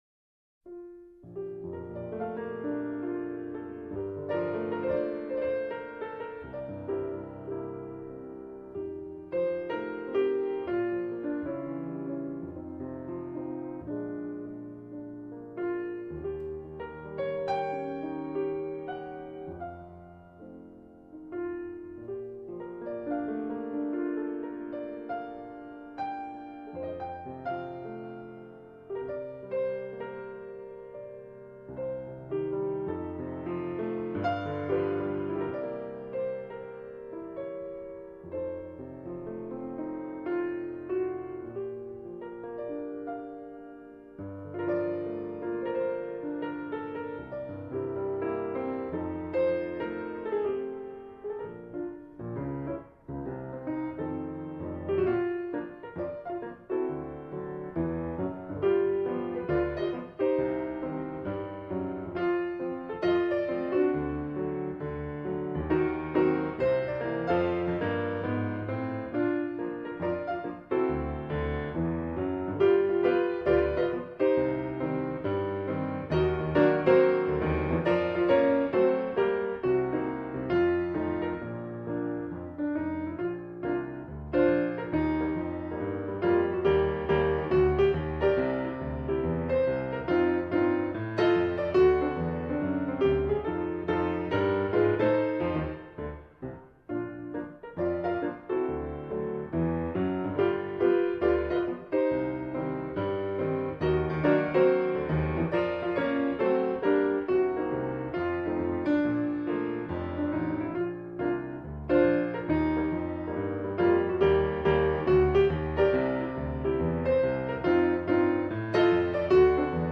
เพลงพระราชนิพนธ์, เปียโน